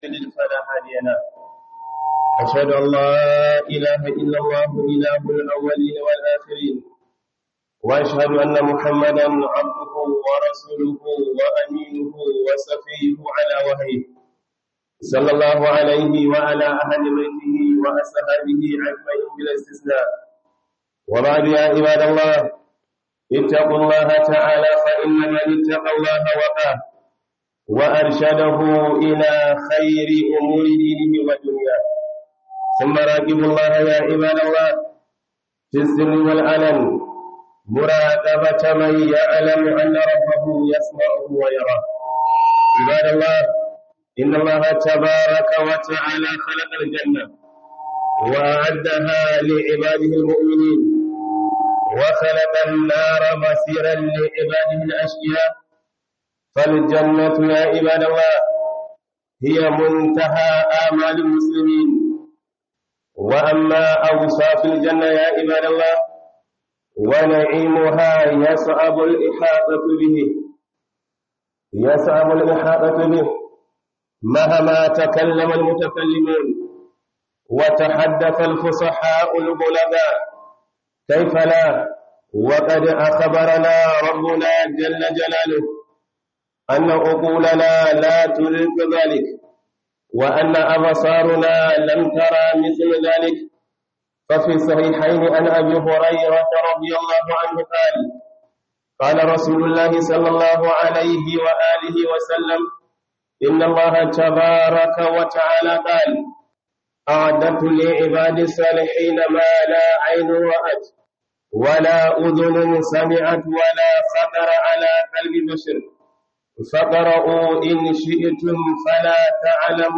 KHUDUBAR JUMA'A